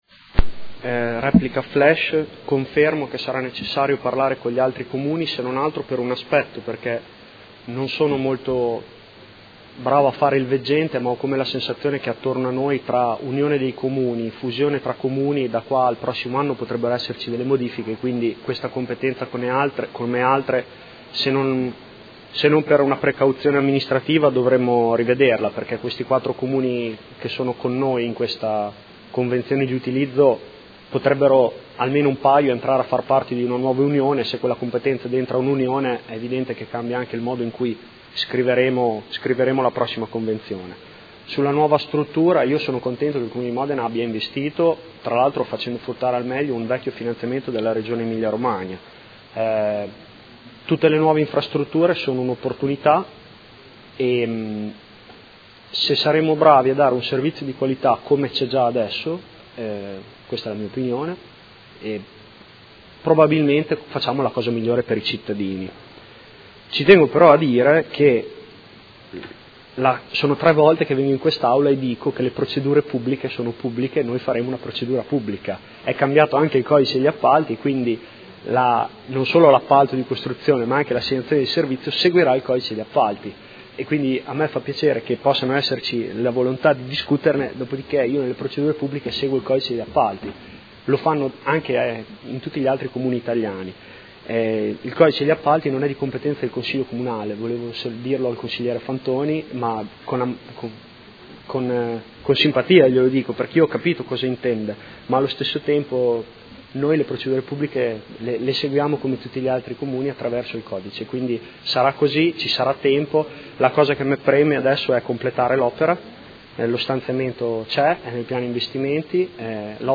Giulio Guerzoni — Sito Audio Consiglio Comunale
Seduta del 19/01/2017 Proposta di deliberazione: Approvazione della Convenzione tra il Comune di Modena e i Comuni di Bastiglia, Castelfranco Emilia, Castelnuovo Rangone e Nonantola, per la gestione dei servizi vari della struttura adibita a Gattile intercomunale. Replica